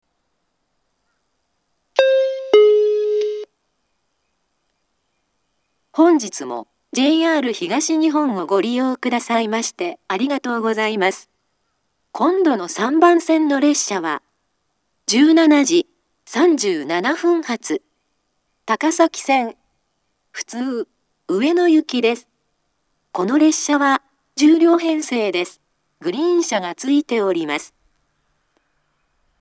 ２０１２年頃には放送装置が更新され、自動放送鳴動中にノイズが被るようになっています。
３番線到着予告放送